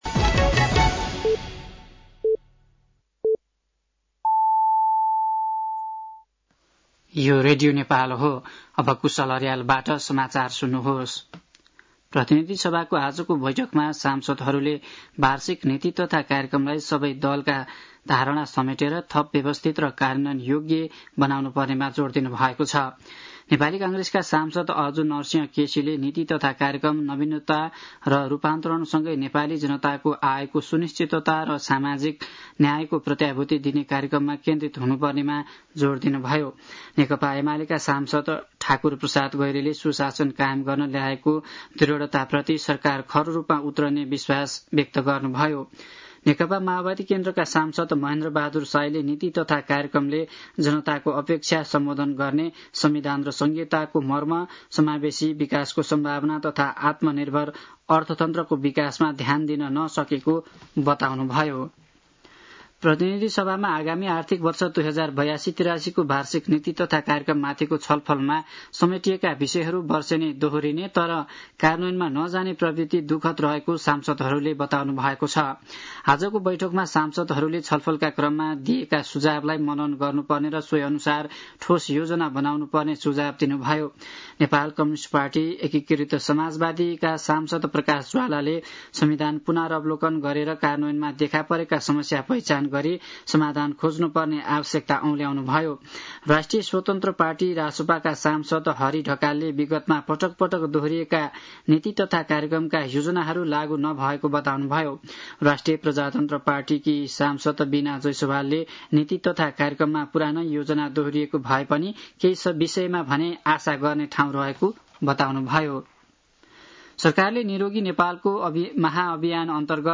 साँझ ५ बजेको नेपाली समाचार : २५ वैशाख , २०८२
5.-pm-nepali-news-.mp3